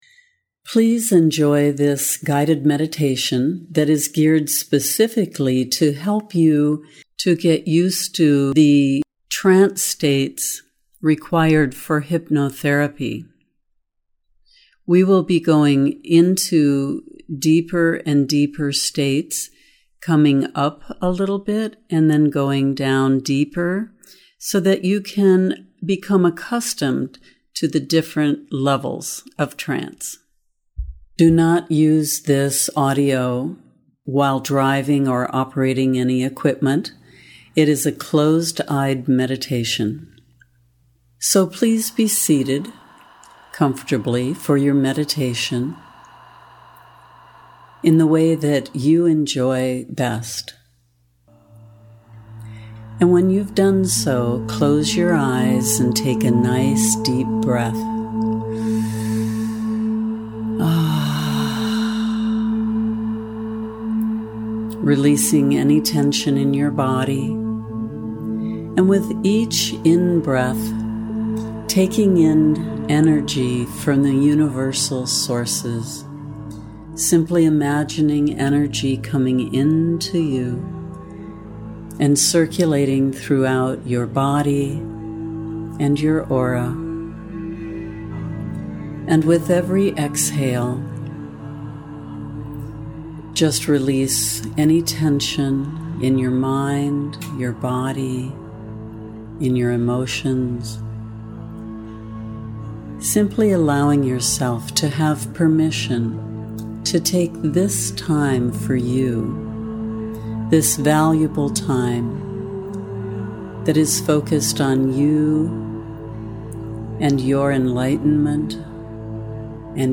Free Hypnotherapy Audio
Deeper-into-Trance-2024-1.mp3